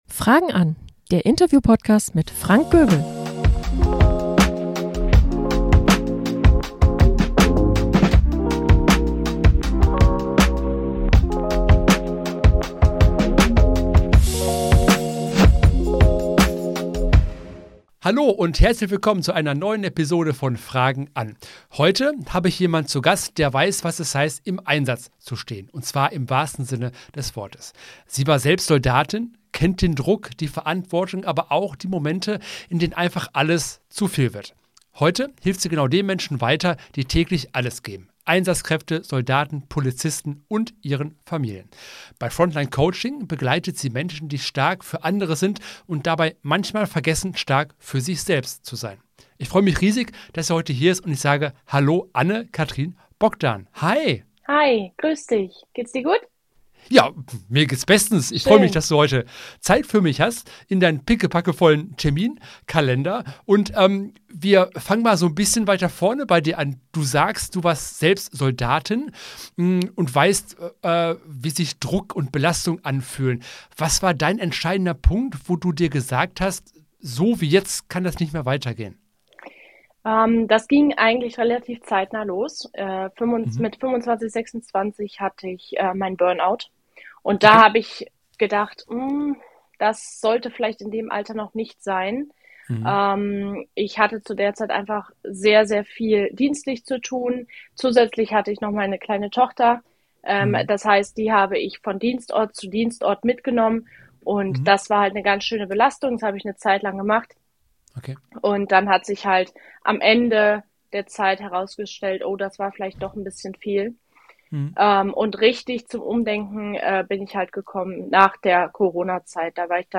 Ich lade euch zu einem sehr interessanten Gespräch ein!